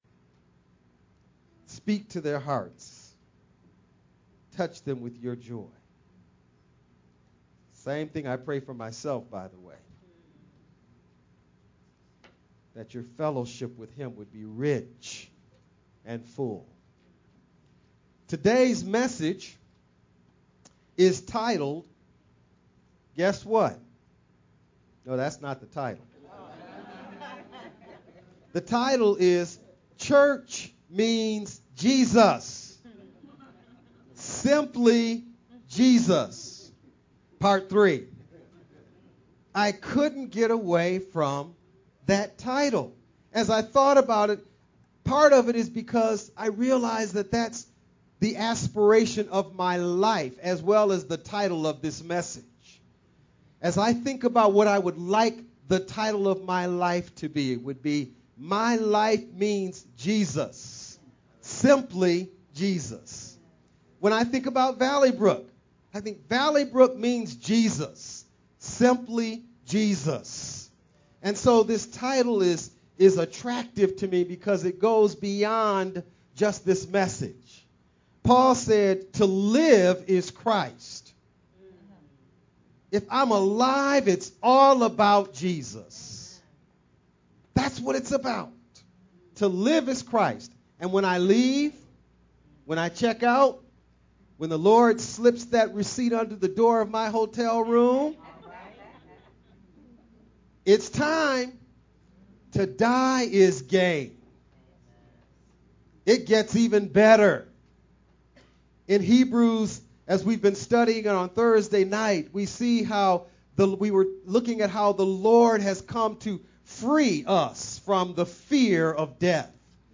2 Corinthians 2: 14-17 You- yes, you- were specifically chosen by God to radiate His glory in a dark world. Message